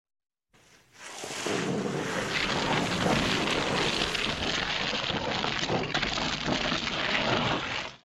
Smooth camera motion and soft ambient sound create a deeply satisfying ASMR experience.